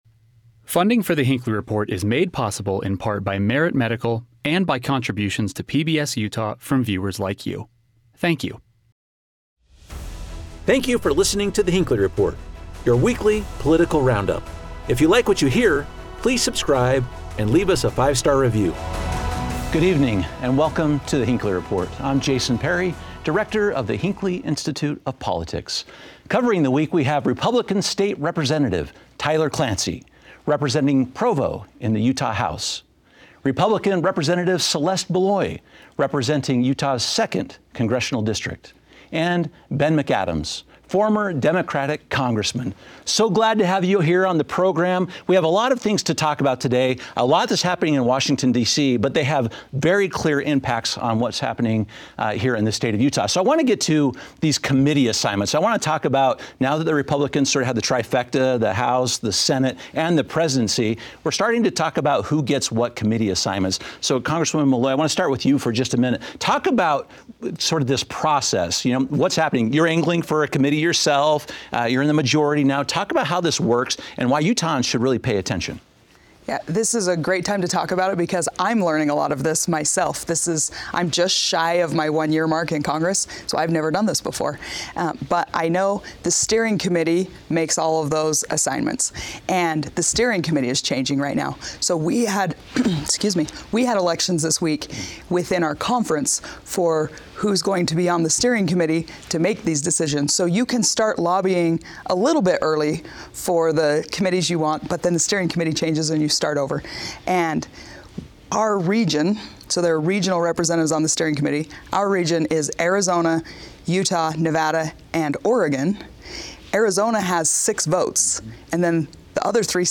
Our panel examines how this behind-the-scenes maneuvering impacts the work of Congress, and how it benefits Utah voters in the long-run. New polling suggests public trust in institutions like Congress and the media is much lower than it used to be. We discuss how this downward trend is a potential threat to our democratic republic, and the best way to improve those relationships.